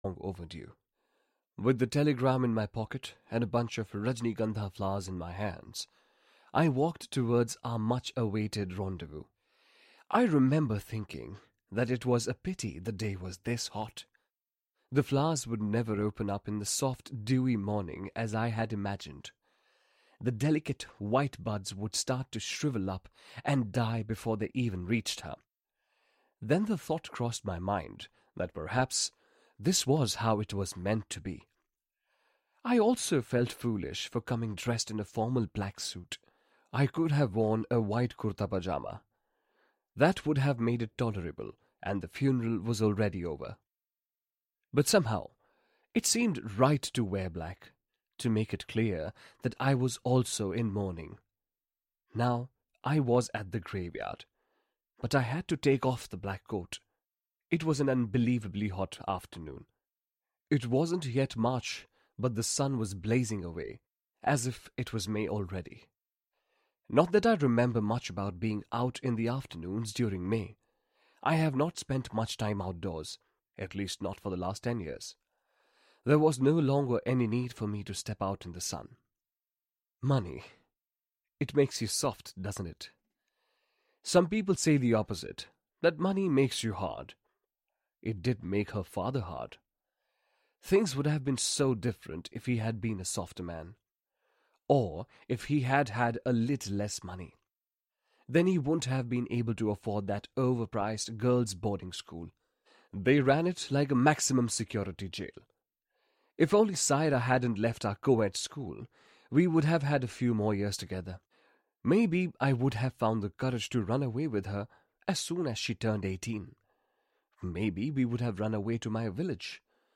Gulab - Annie Zaidi - Hörbuch